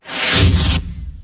cloak05.wav
cloack sound FOR RA2